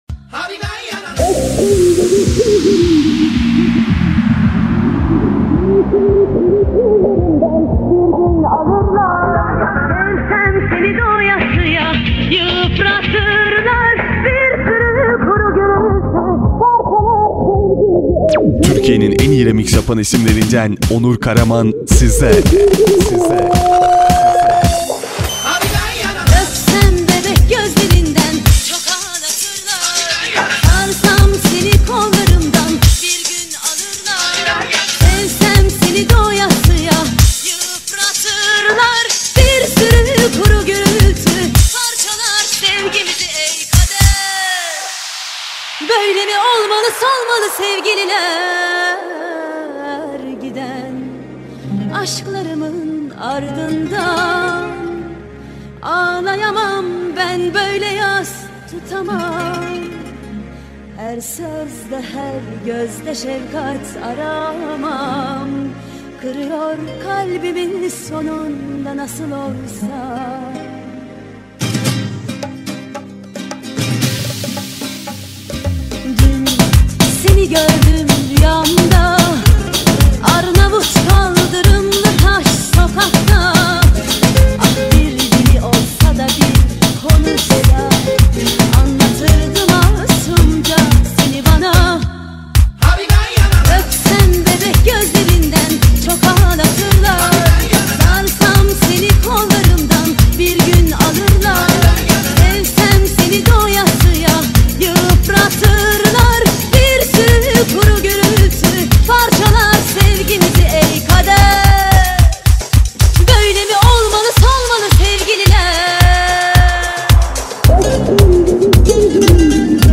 ژانر: پاپ & رپ